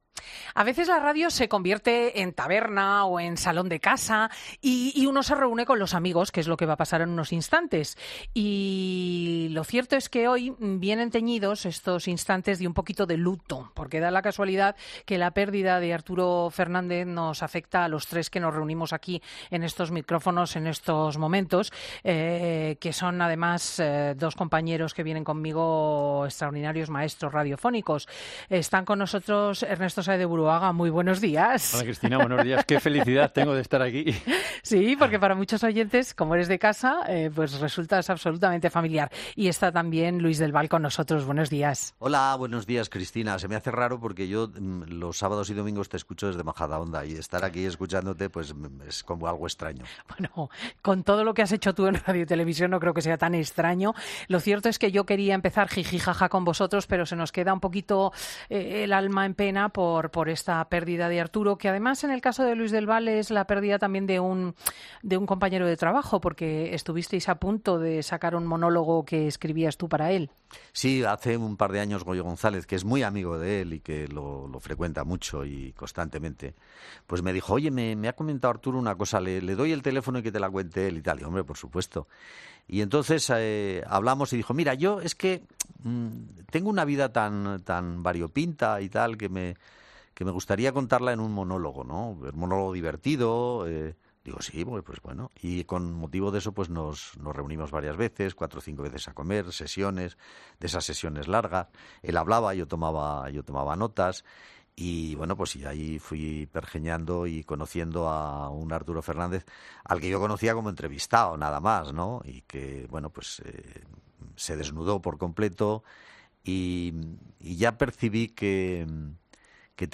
Luis del Val y Ernesto Sáenz de Buruaga hablan de su libro en 'Fin de Semana'